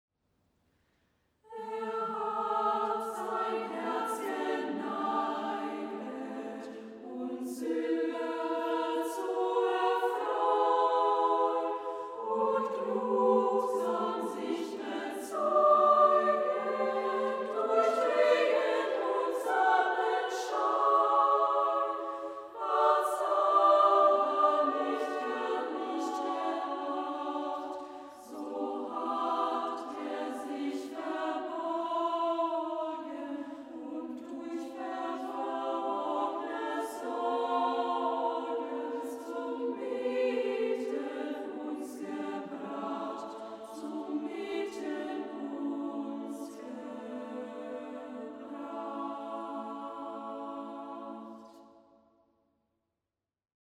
Werke für Frauen- und Kinderchor und Sololieder